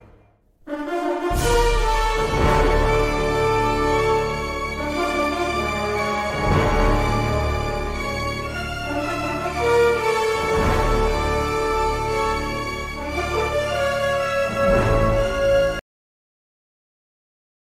Kategorien: Filmmusik